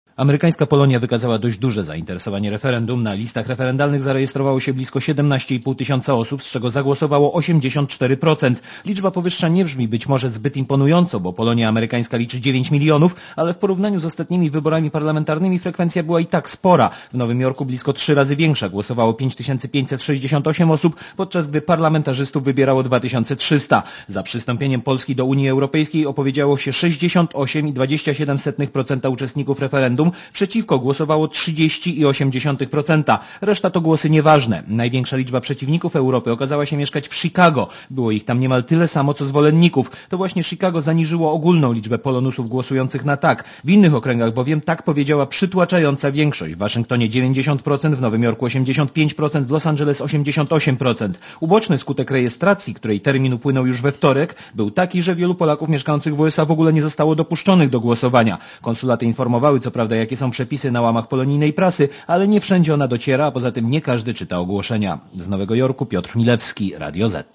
Korespondencja z Nowego Jorku (265Kb)